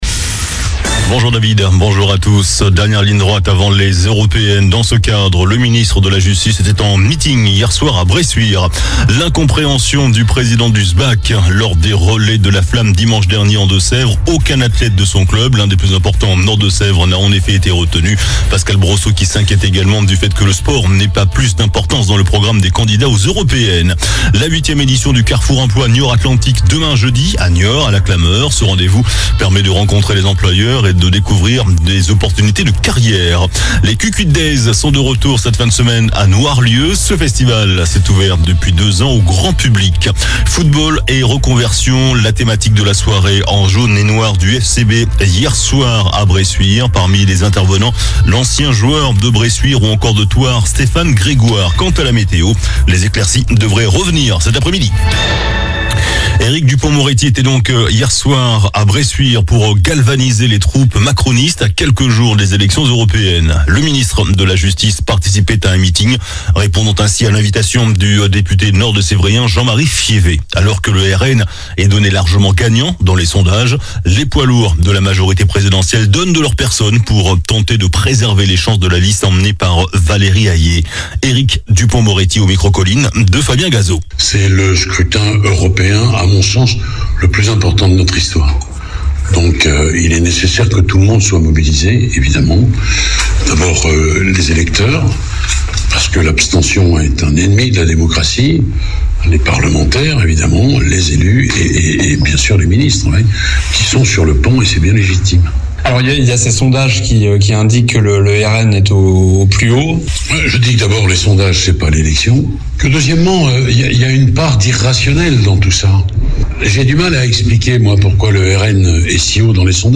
JOURNAL DU MERCREDI 05 JUIN ( MIDI )